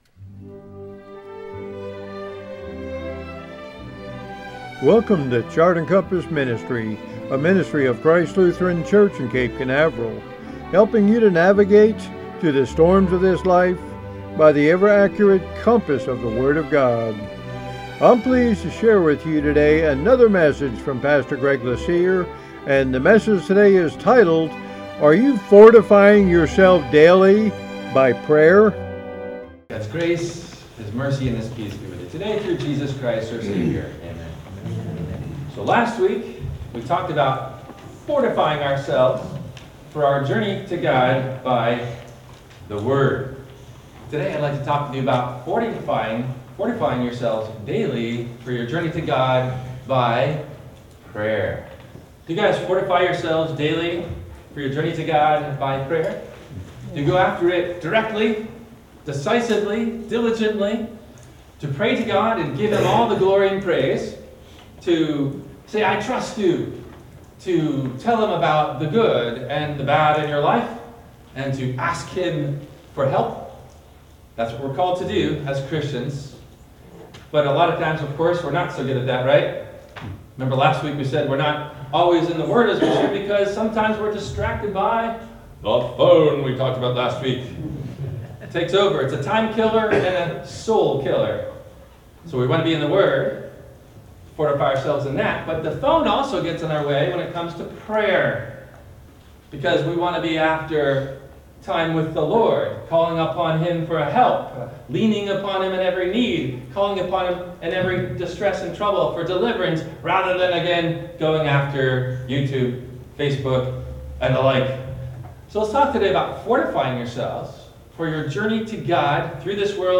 Are you FORTIFYING yourself for your journey through this world to God? – WMIE Radio Sermon – January 19 2026